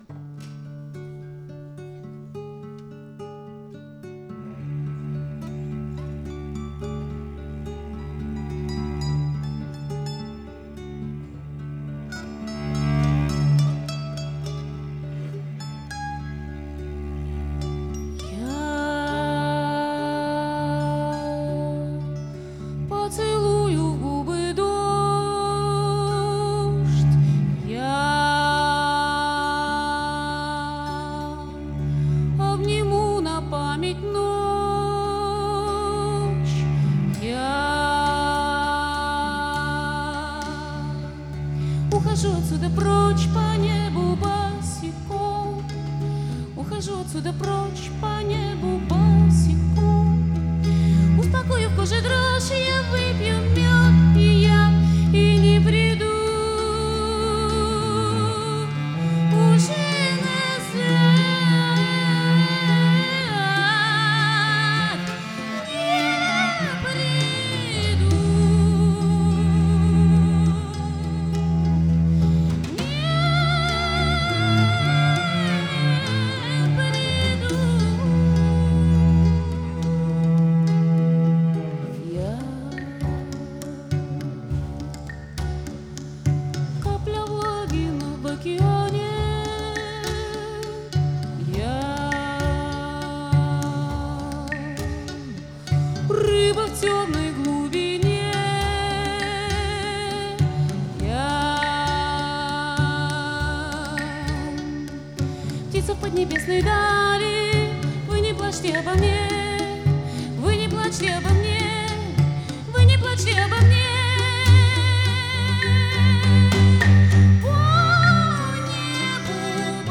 Концертный двойник, стиль — акустика.
голос, гитара
виолончель, голос
флейты, гитары, перкуссия, голос
мандолина, голос
перкуссия, голос